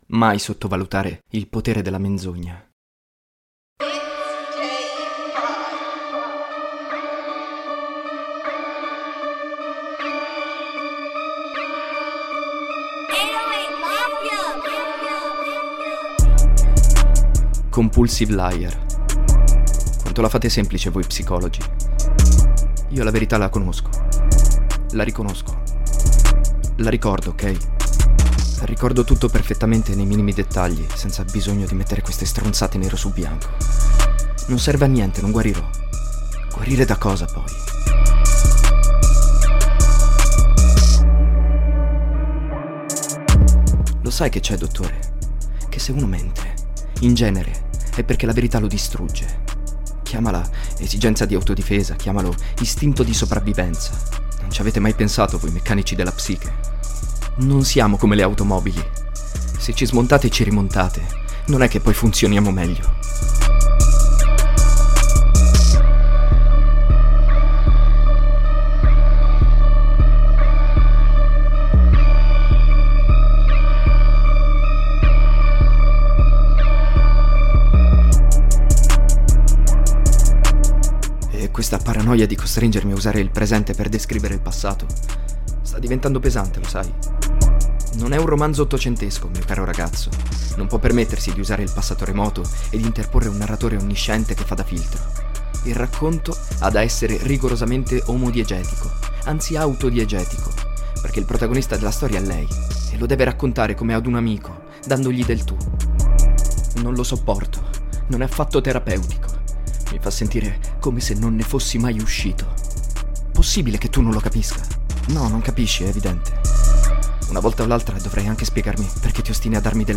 The narrative voice is that of Emmanuel himself